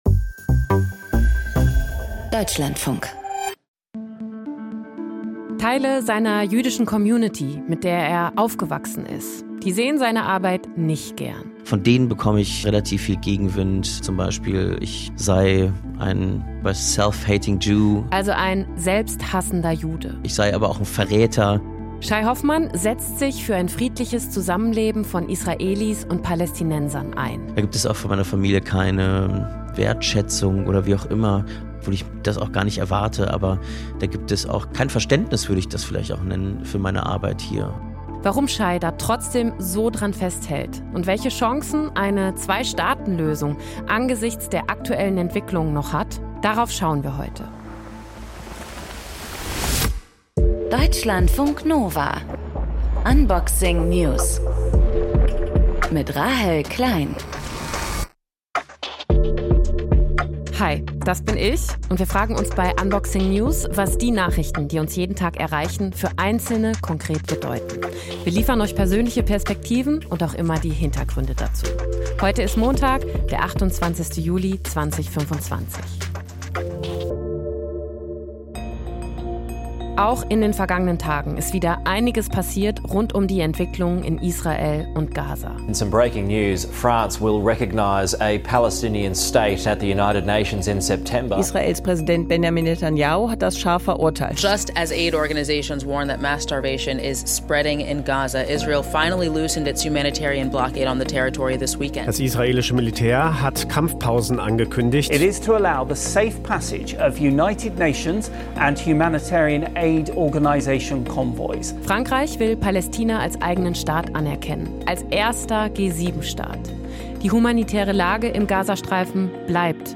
The Food Professor Podcast, recorded live at the SIAL Canada food innovation show in Toronto